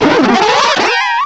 Add all new cries
cry_not_floatzel.aif